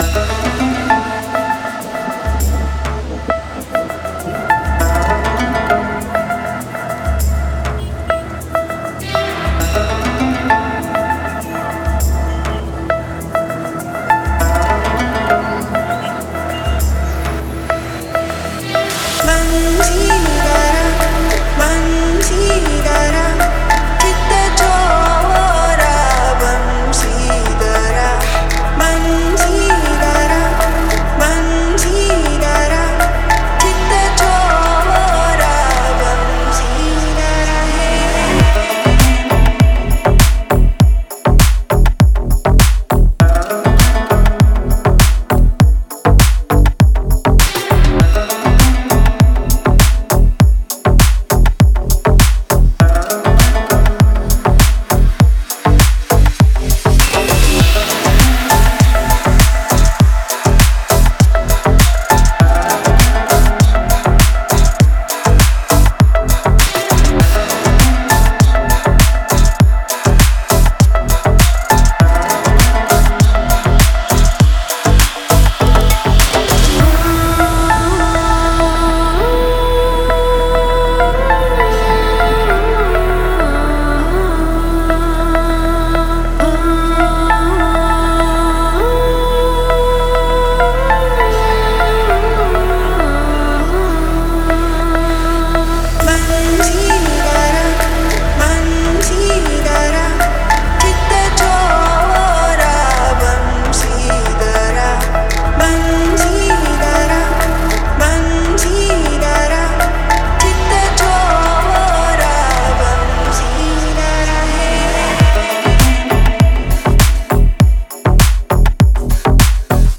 это завораживающая композиция в жанре прогрессив-хаус
насыщенными синтезаторами и ритмичными битами